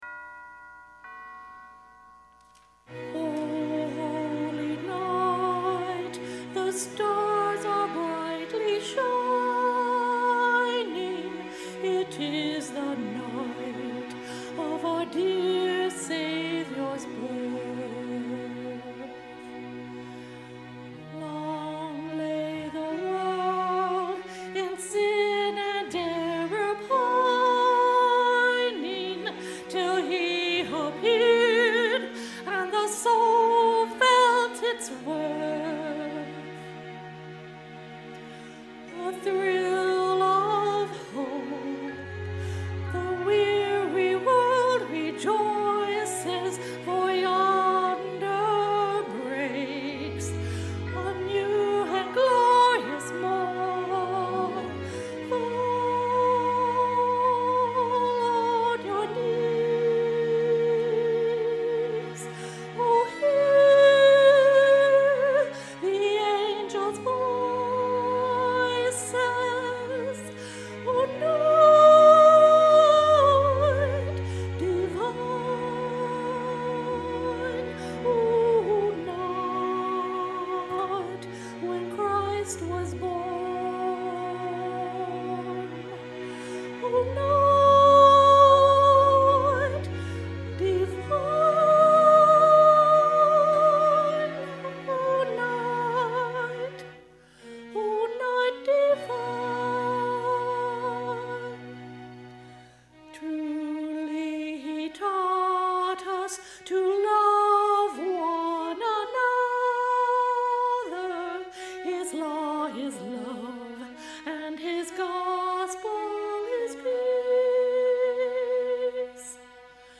Sermons
As the Proclaiming Angel